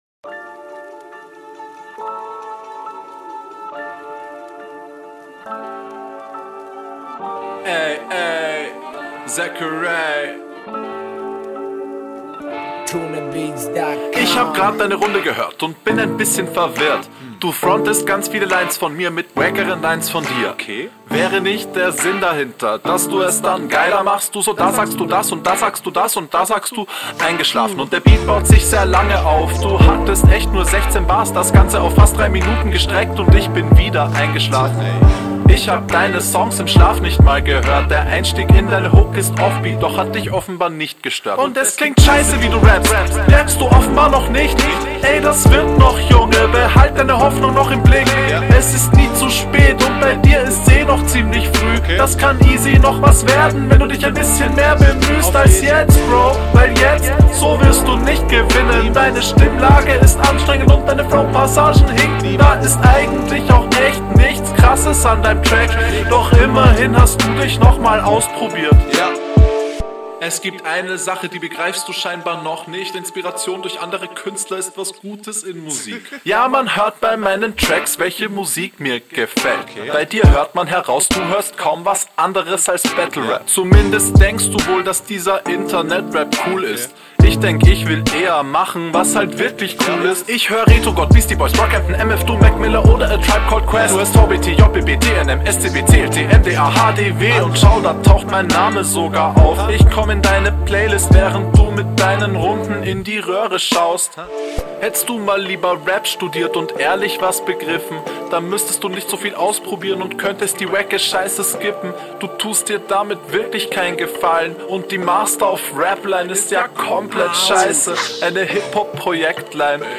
Deutlich arroganter, gemeiner aber irgendwie auch gelassener.